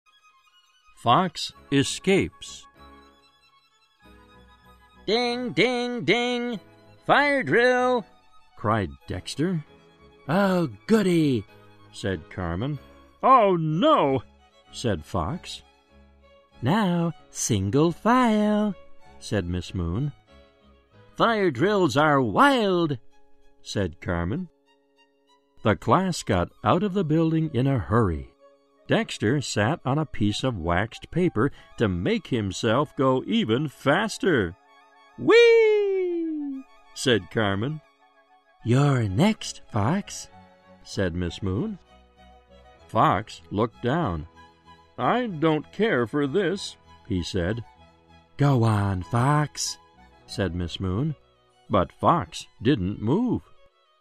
在线英语听力室小狐外传 第35期:小狐逃跑的听力文件下载,《小狐外传》是双语有声读物下面的子栏目，非常适合英语学习爱好者进行细心品读。故事内容讲述了一个小男生在学校、家庭里的各种角色转换以及生活中的趣事。